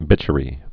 (bĭchə-rē)